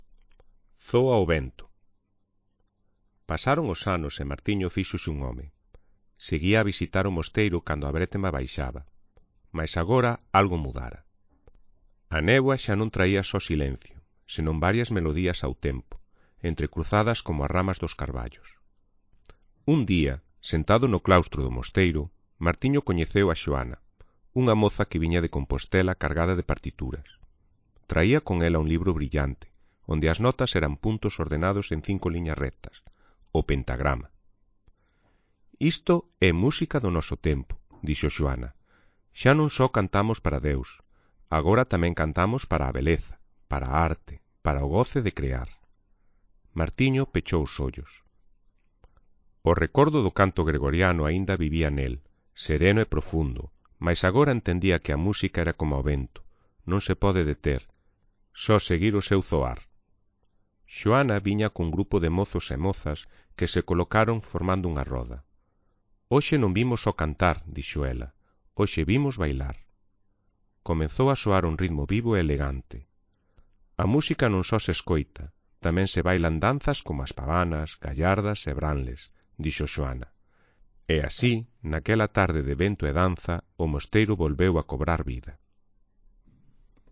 Zoa_o_vento_-_baixa_calidade.mp3